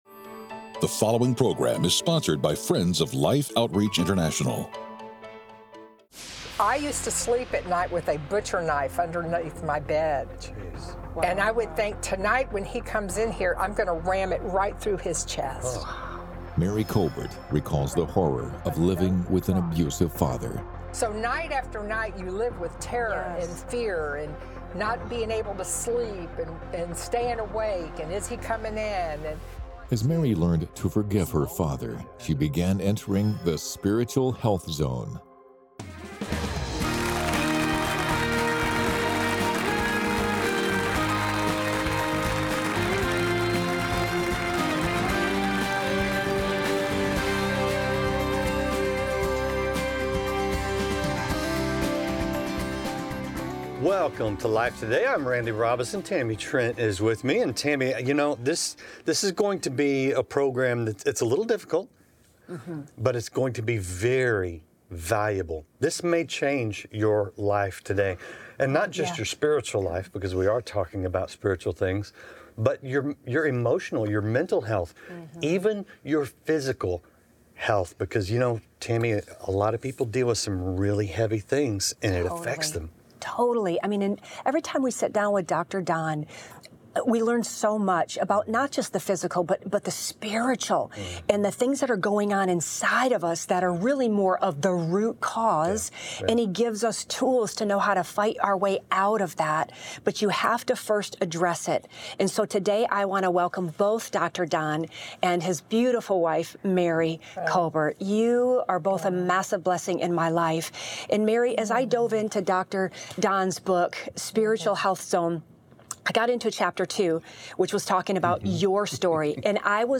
A woman who suffered abuse in her childhood shares the process of emotional and spiritual cleansing that freed her from lingering pain.